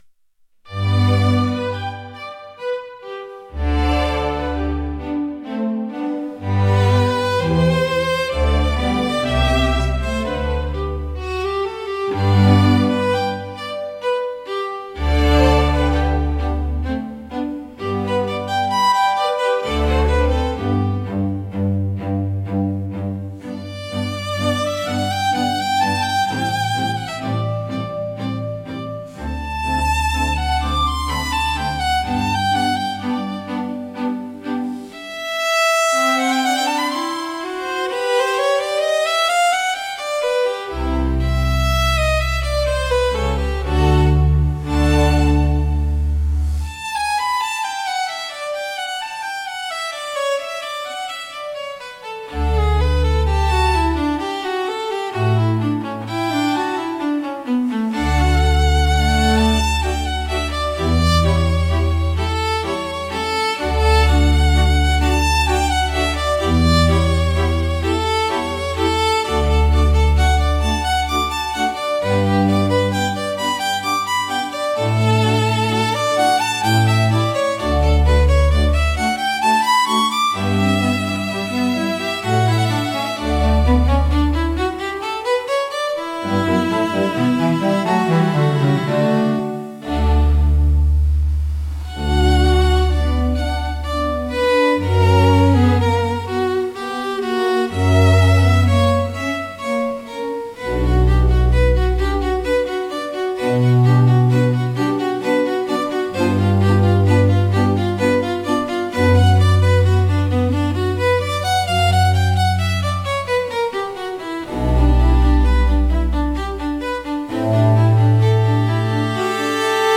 聴く人に高雅さや荘厳さ、心の深みを感じさせる普遍的なジャンルです。